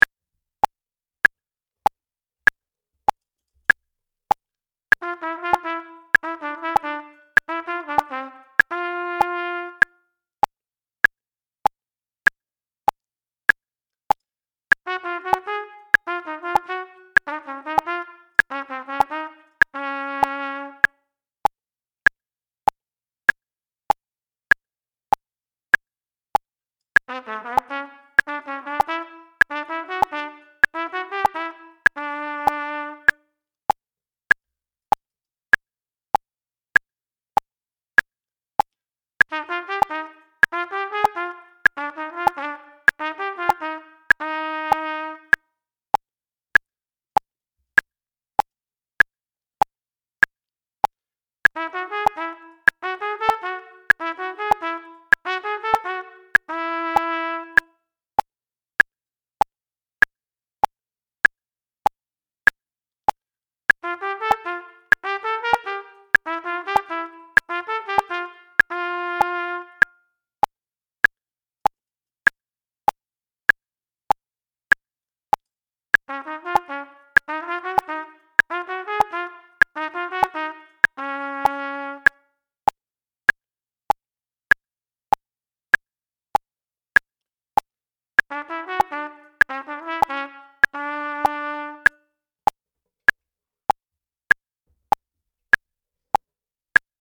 Triple Tonguing Exercises
The click track has been removed in level two so that let you may more easily check the evenness of your notes.